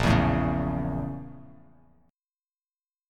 BbmM7b5 chord